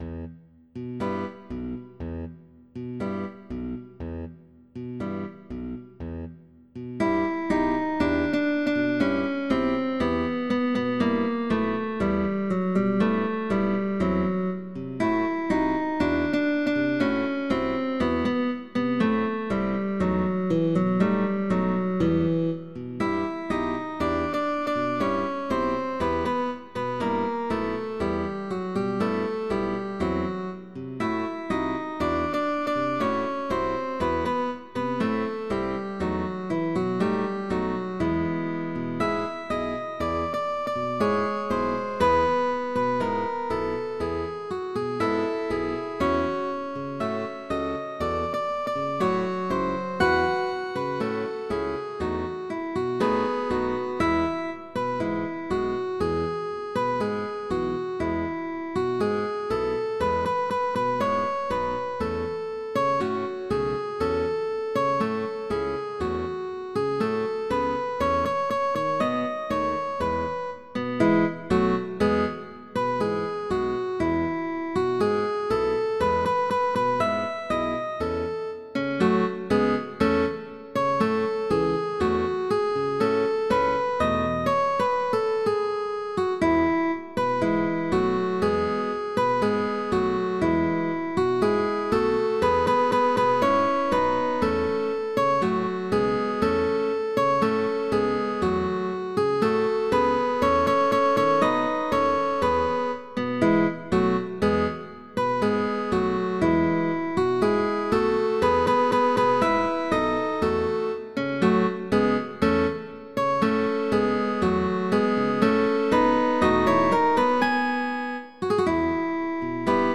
CUARTETO DE GUITARRAS
Con Bajo opcional, válido para orquesta de guitarras.